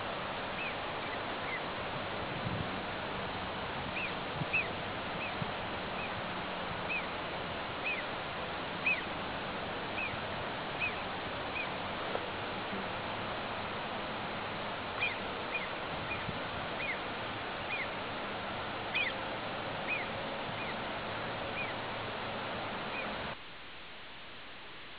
Se ti accontenti di suoni rudimentali posso provare a mettere l'audio registrato di una
marmotta della val Cedec (Parco Nazionale dello Stelvio)
...mettersi vicino ad un ruscello non è stata una grande idea...